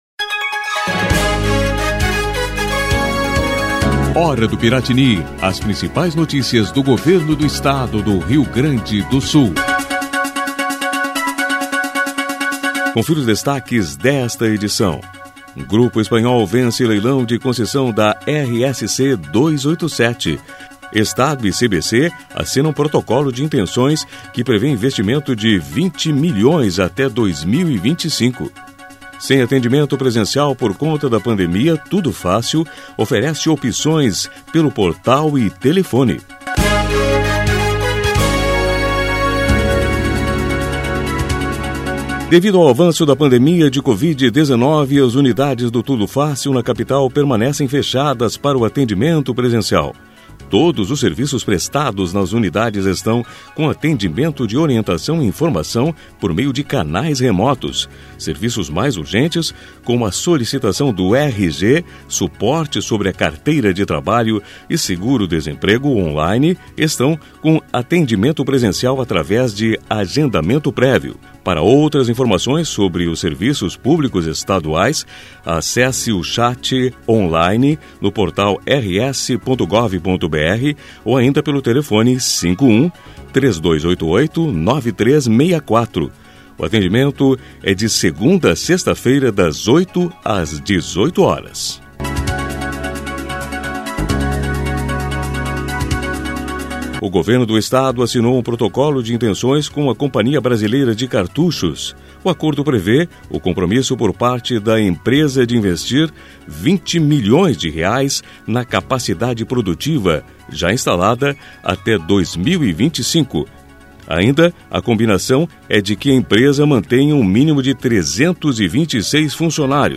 A Hora do Piratini é uma síntese de notícias do Governo do Estado, produzida pela Secretaria de Comunicação.